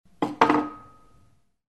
Звуки стакана
Стеклянный стакан поставили на стол раздался звук